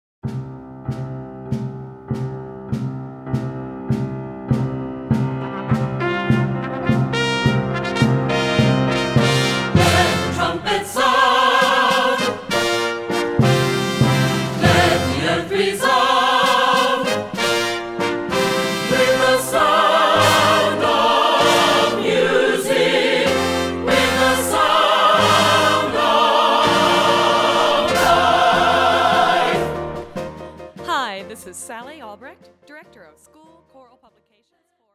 secular choral